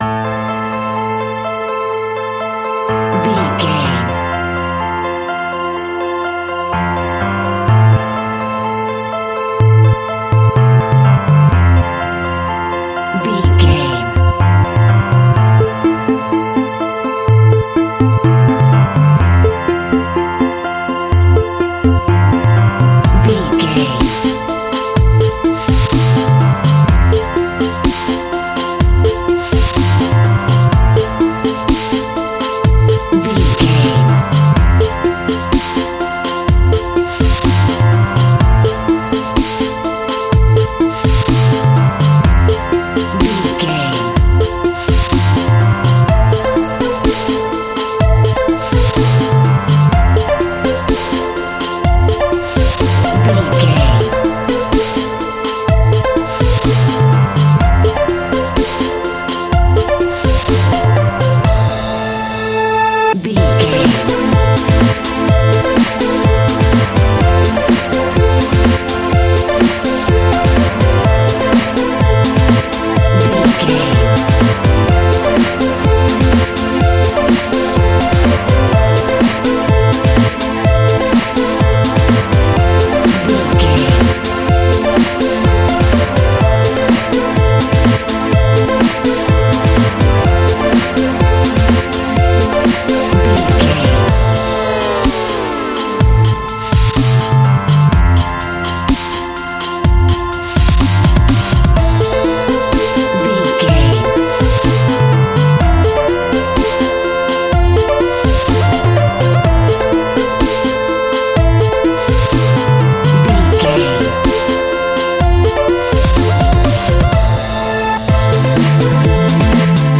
Epic / Action
Fast paced
Aeolian/Minor
dark
futuristic
groovy
aggressive
electric organ
piano
electric piano
synthesiser
drums
drum machine
electronica
techno music
synth bass
synth pad
robotic